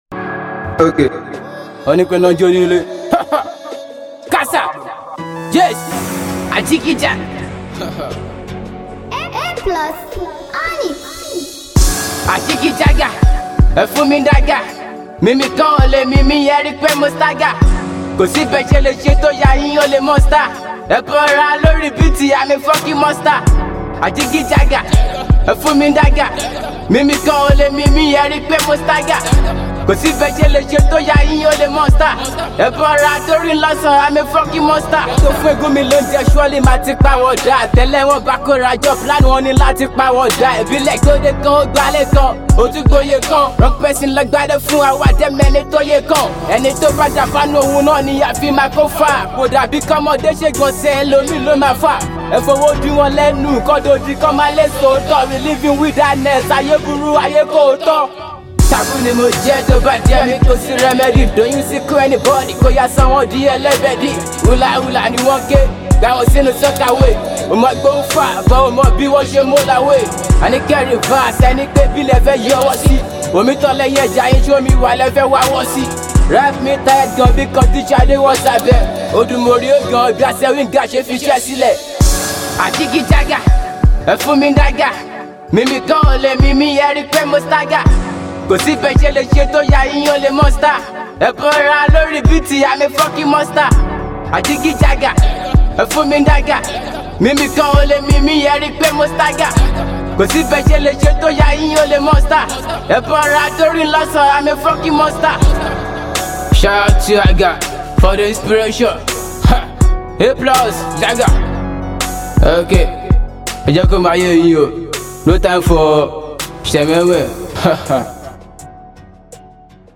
rap
hiphop